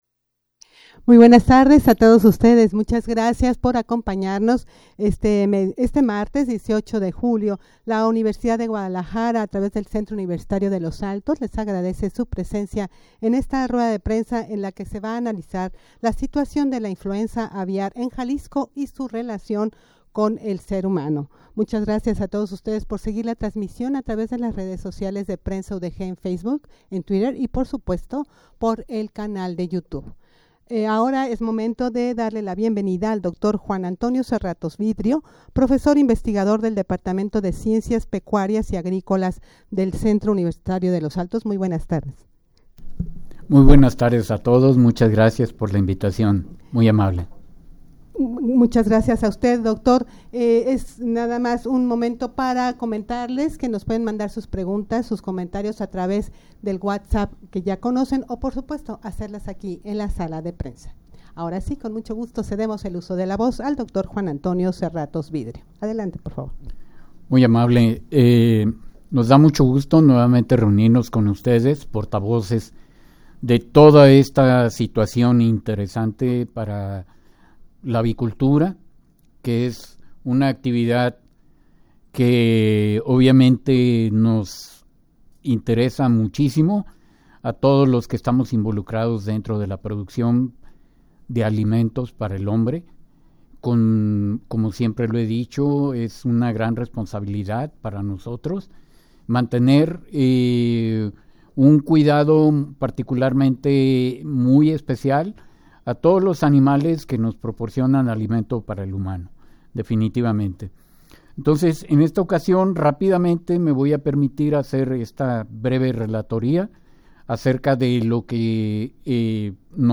Audio de la Rueda de Prensa
rueda-de-prensa-para-analizar-la-situacion-de-la-gripe-aviar-en-jalisco-y-su-relacion-con-el-ser-humano.mp3